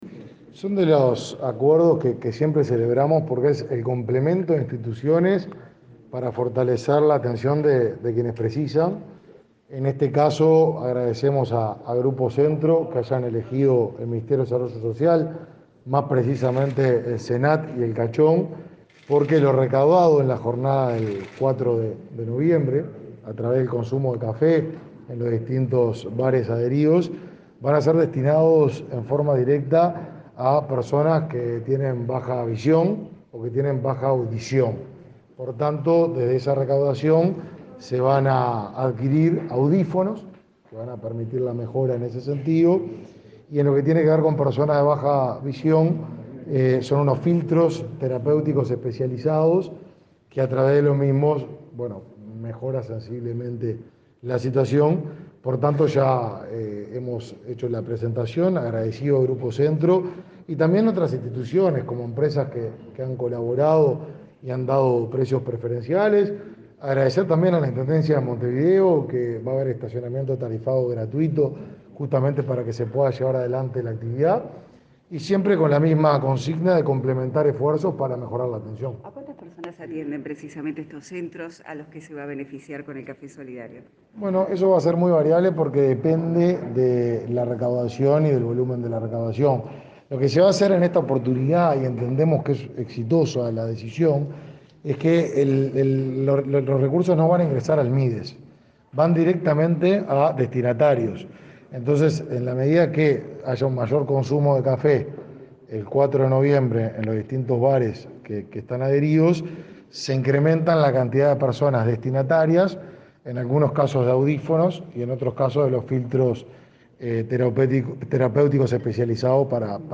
Declaraciones a la prensa del ministro de Desarrollo Social, Martín Lema
Declaraciones a la prensa del ministro de Desarrollo Social, Martín Lema 01/11/2021 Compartir Facebook X Copiar enlace WhatsApp LinkedIn Tras participar en la presentación de la campaña solidaria que permitirá recaudar fondos para el Centro Nacional de Ayudas Técnicas y Tecnológicas y el instituto Tiburcio Cachón, el ministro de Desarrollo Social efectuó declaraciones a la prensa.